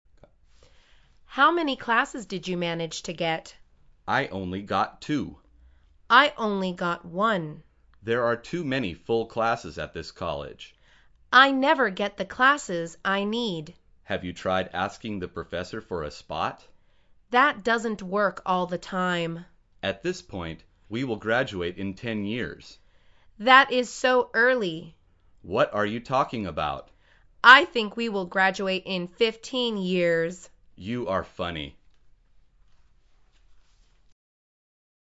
مجموعه مکالمات ساده و آسان انگلیسی – درس شماره سوم از فصل زندگی محصلی: کلاس های پر شده‌ی زیاد